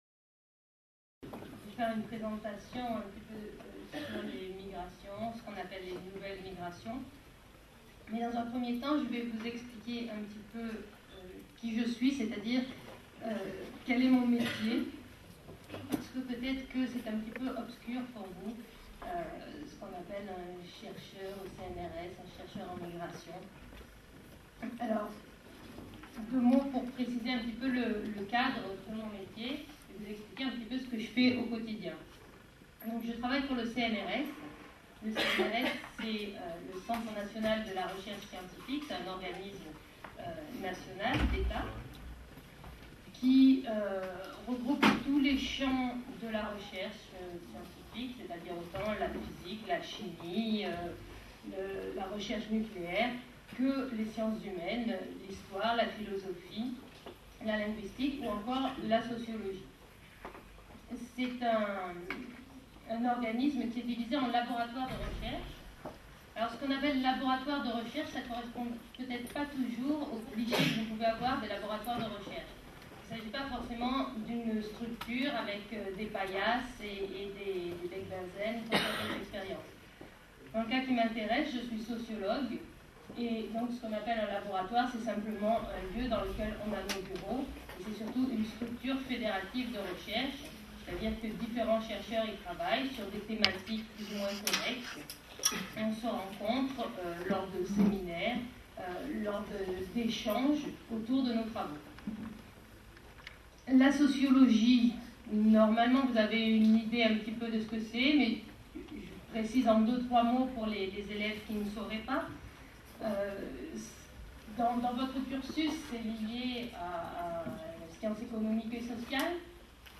Une conférence de l'UTLS au Lycée Mouvement et migrations